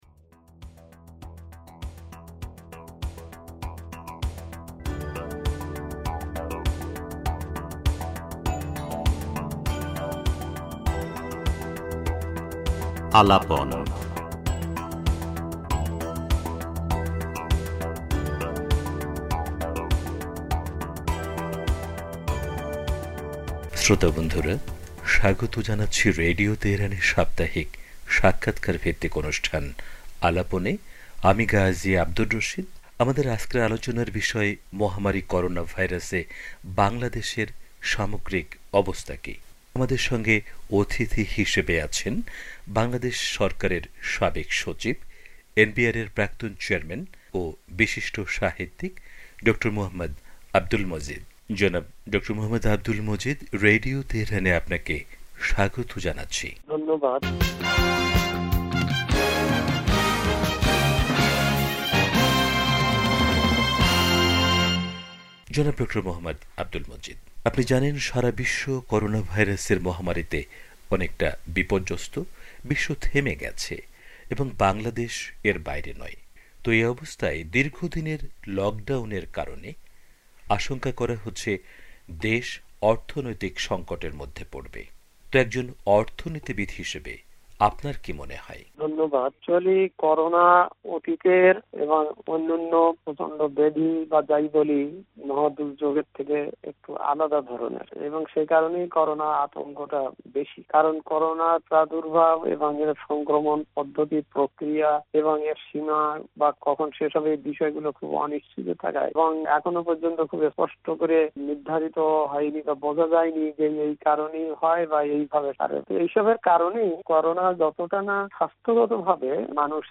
রেডিও তেহরানকে দেয়া সাক্ষাৎকারে